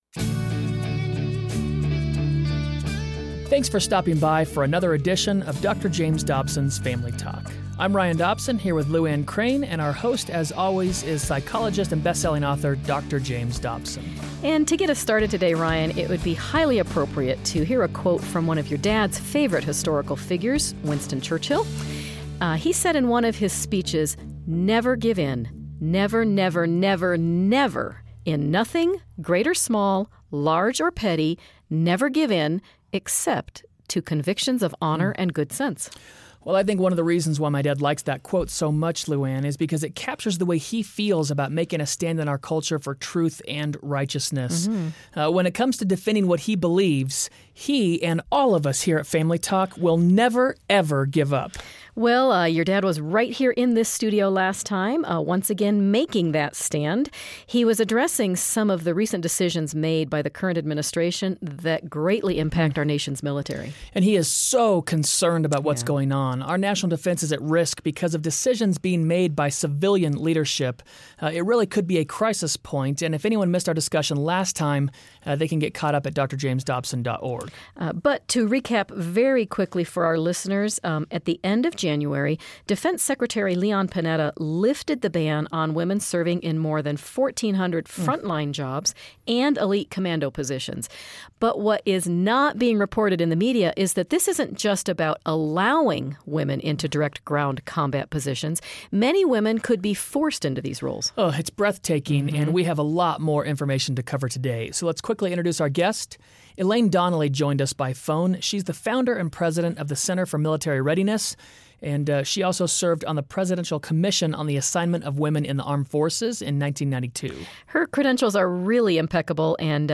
Join in on a provocative discussion examining the recent decision to force women into direct combat positions. Learn how we can protect our wives and Daughters from a misguided liberal agenda.